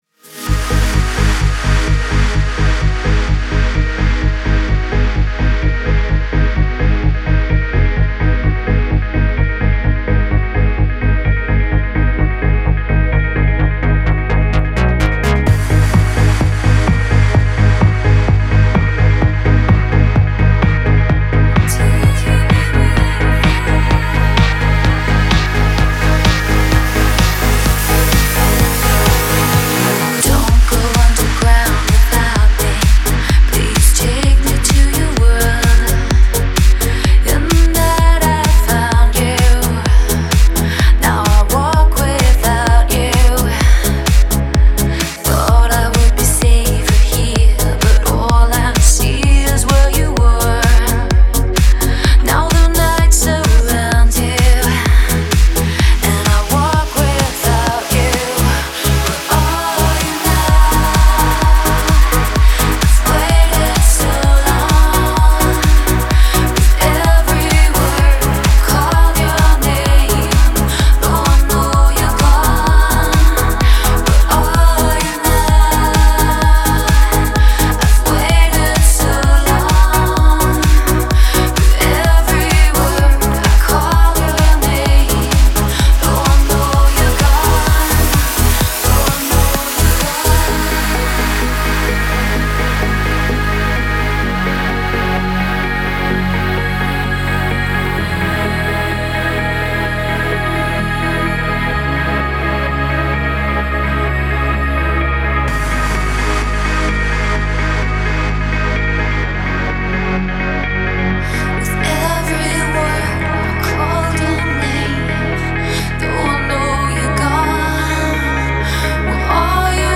E ... 4:06 Жанр: Dance / House / Electro Плейлист: Trance.